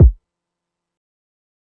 Codeine Crazy Kick.wav